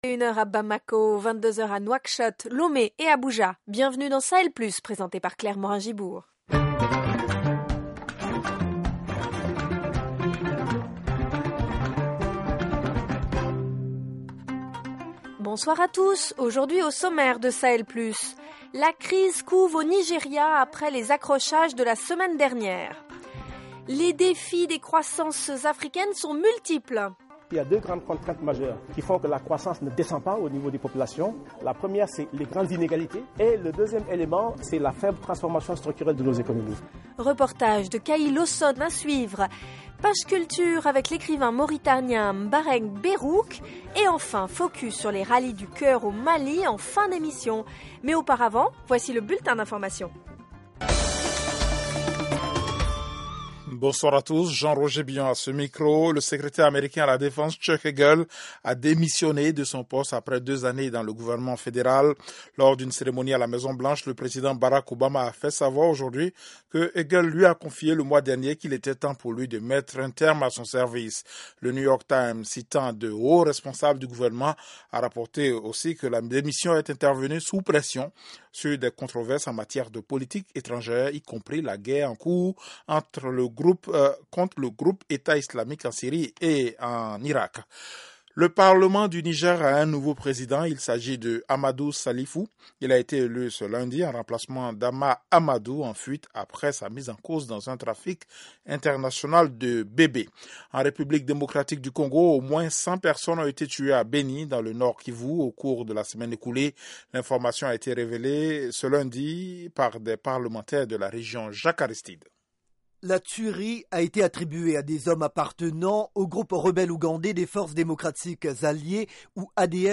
Au programme : la crise couve au Nigéria après les accrochages de la semaine dernière. Economie : les défis des croissances africaines sont multiples. Reportage